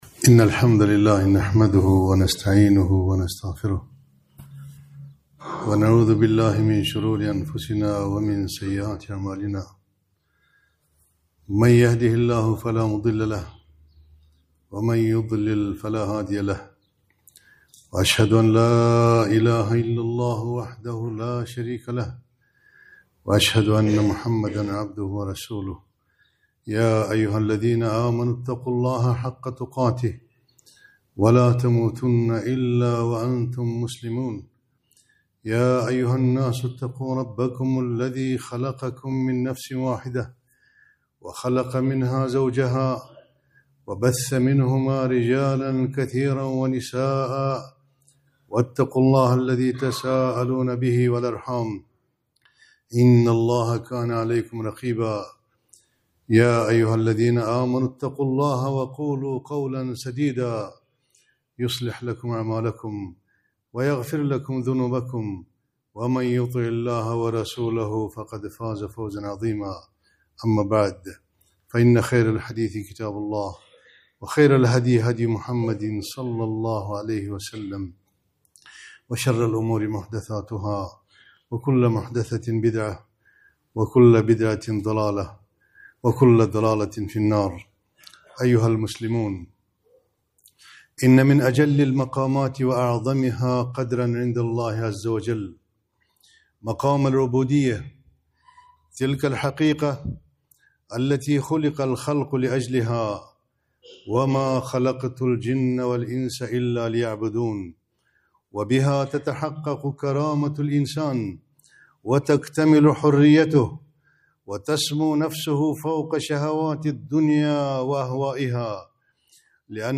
خطبة - العبودية